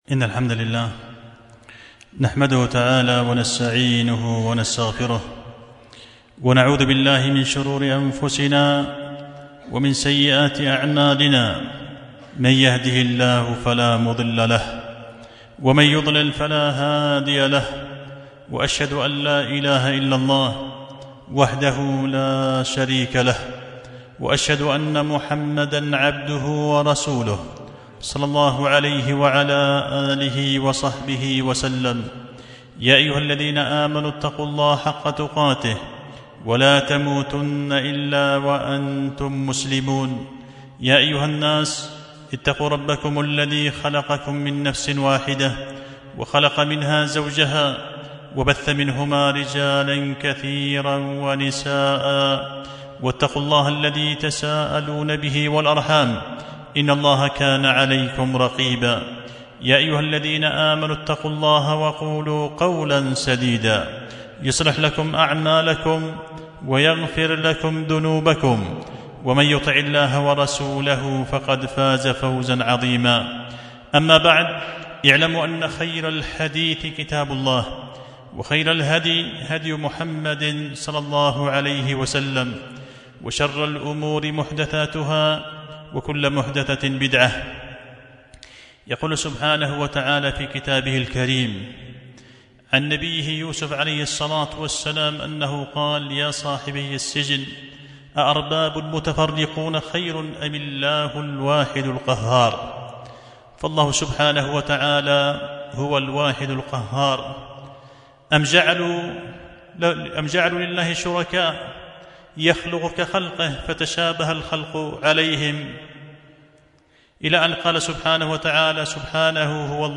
خطبة جمعة بعنوان فيضان الأنهار في بيان سوء عاقبة من حارب الواحد القهار